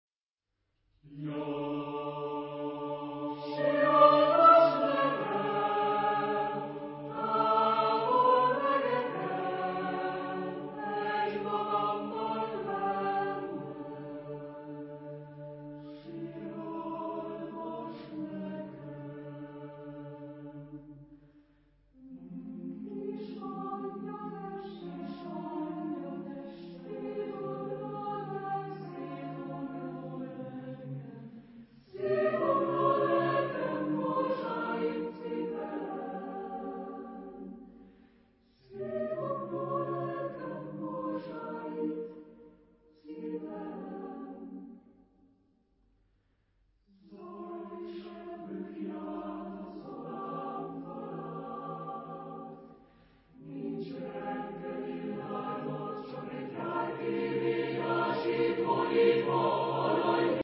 Género/Estilo/Forma: Profano ; Lírica ; Coro
Tipo de formación coral: SATB  (4 voces Coro mixto )
Tonalidad : centros tonales